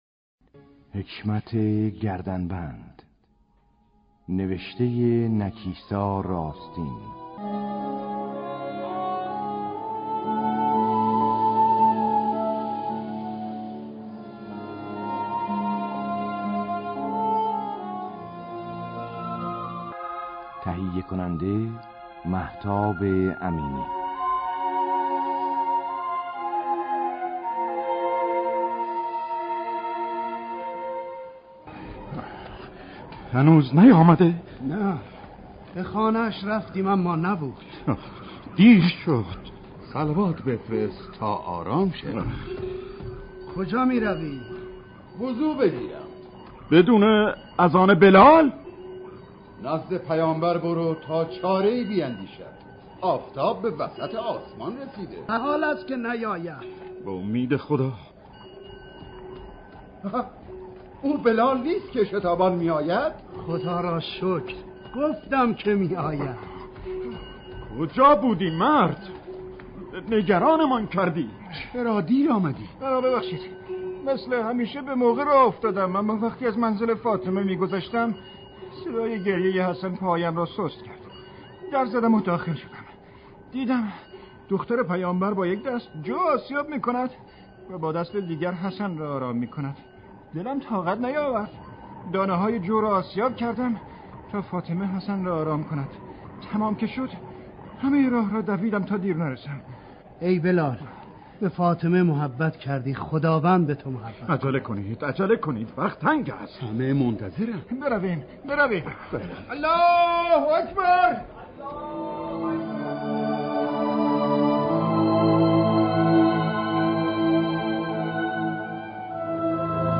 پخش دو نمایش رادیویی ویژه‌ی شهادت حضرت فاطمه زهرا